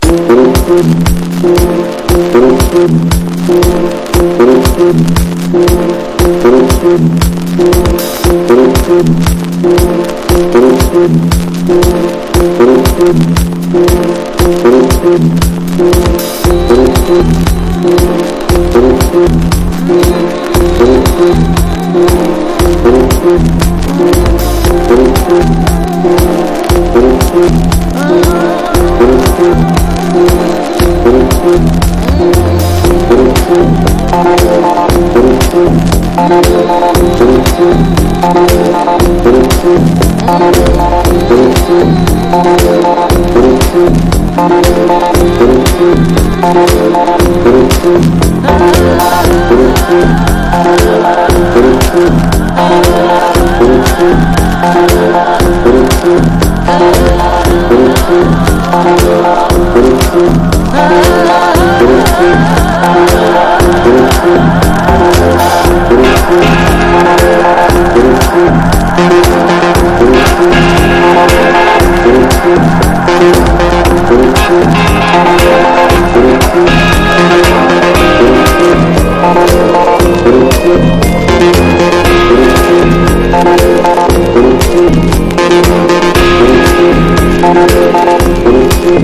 DEEP HOUSE / EARLY HOUSE# NU-DISCO / RE-EDIT